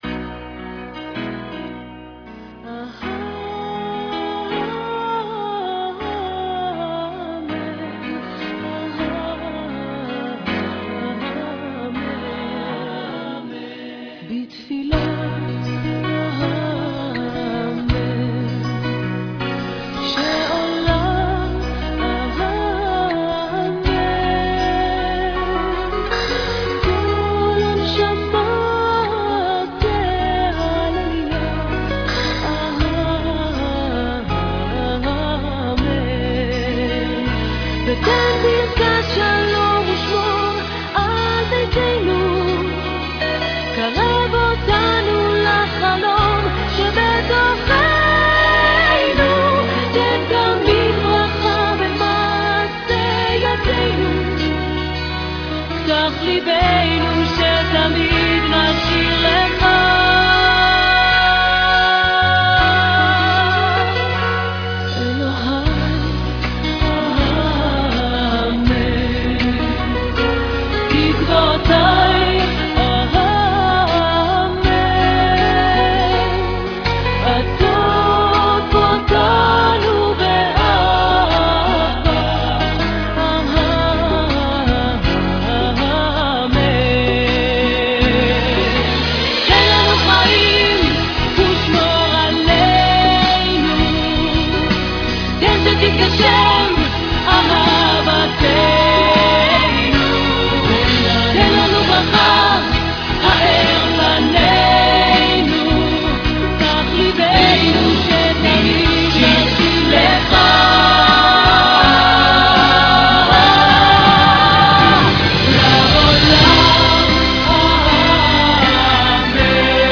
Dublin, Ireland
at Eurovision 1995
was sang by a young and unfamiliar singer at the time